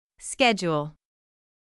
※当メディアは、別途記載のない限りアメリカ英語の発音を基本としています。
【初級 /s/の発音】
/ˈskɛʤʊl/